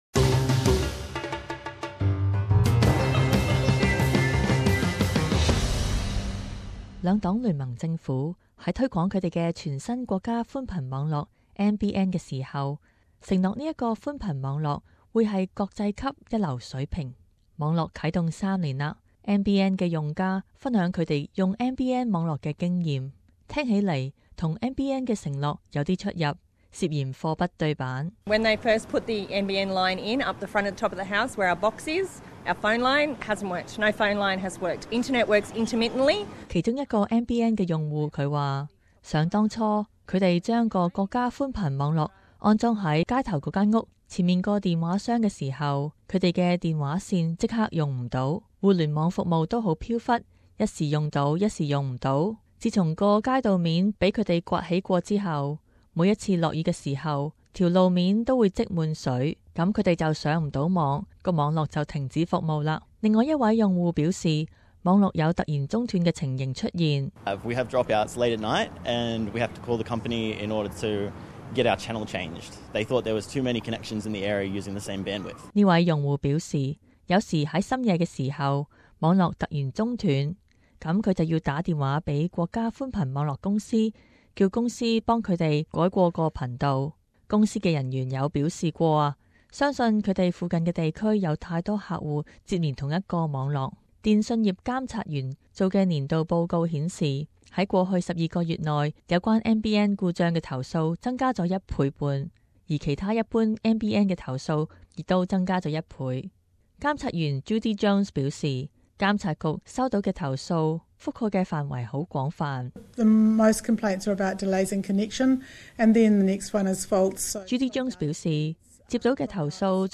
【時事報導】全國寬頻網絡投訴多多